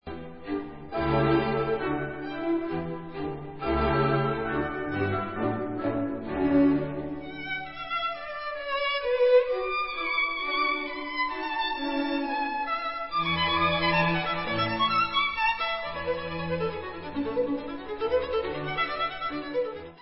housle
Koncert pro housle a orchestr č. 1 D dur, op. 3:
Rondo. Tempo di Menuetto (Allegro)